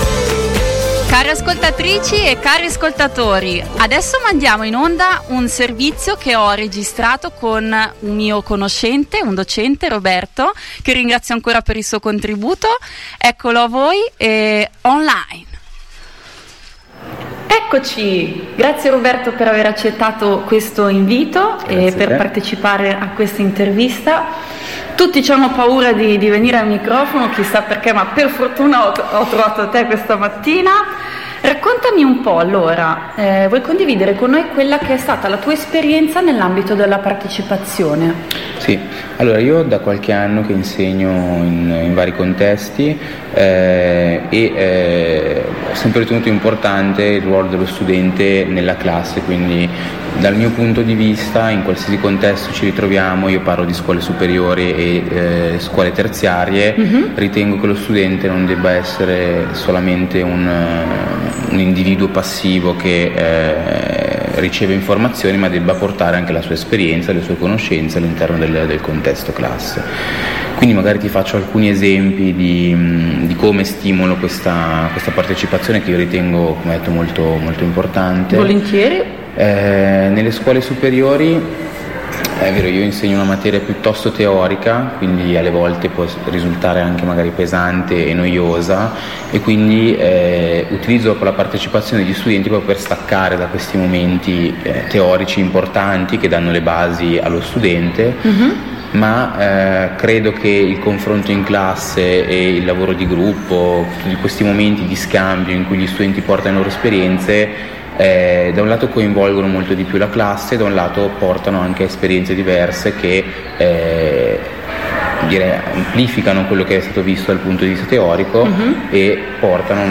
Dalle 8.00 Interviste ai partecipanti alla Giornata ESS 2019.